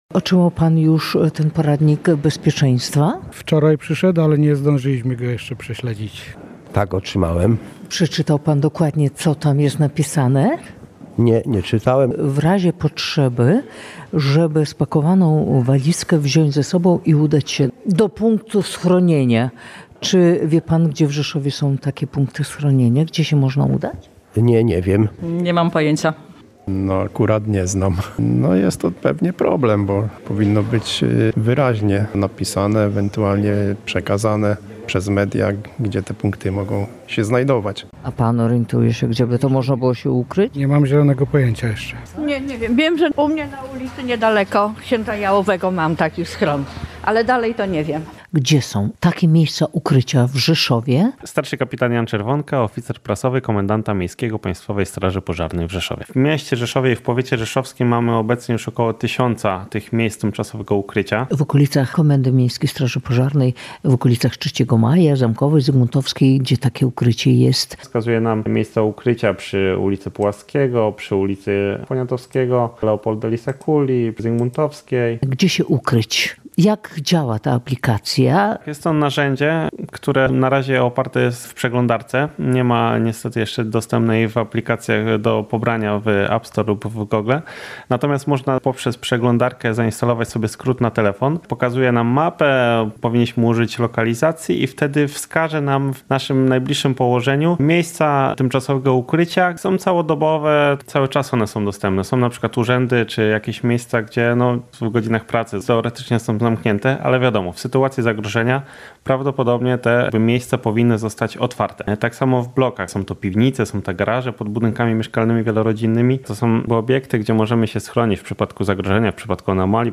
Pytani przez nas mieszkańcy miasta nie wiedzą o istnieniu takich miejsc.